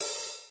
hihat-open.wav